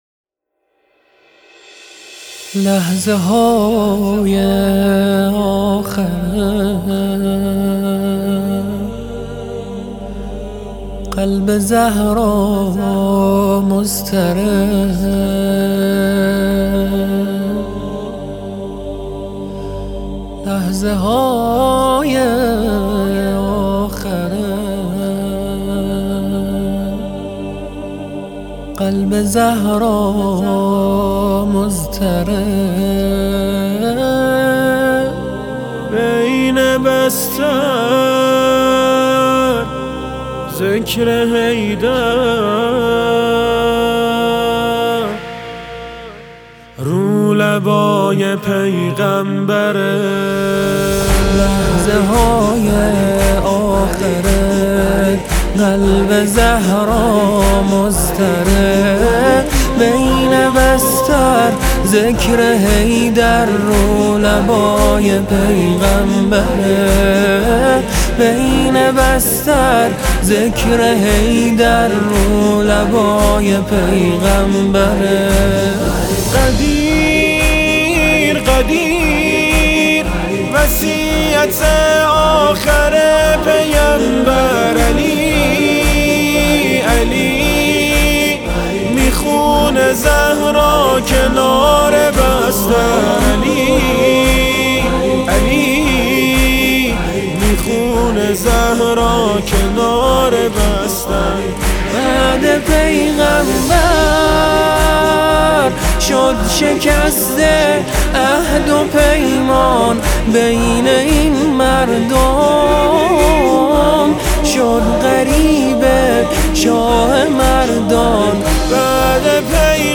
نماهنگ مذهبی